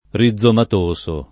rizomatoso [ ri zz omat 1S o ] agg. (bot.)